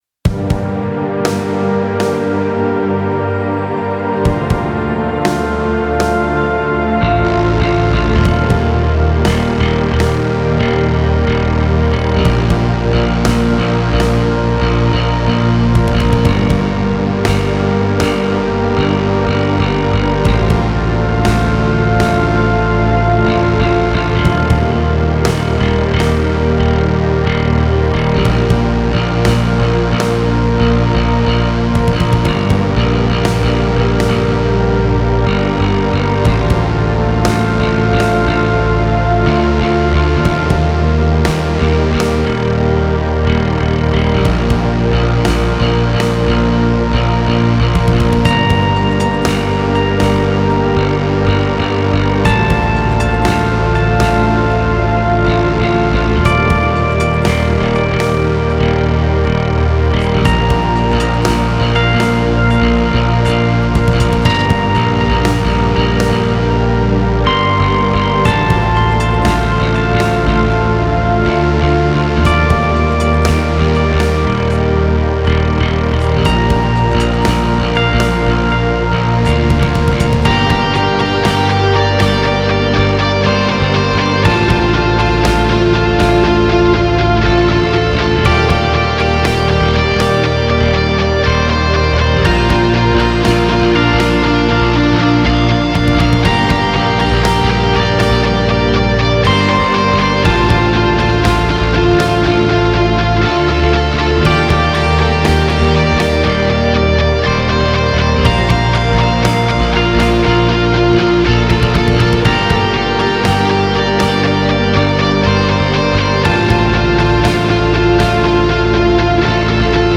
Post-rock
آهنگ بیکلام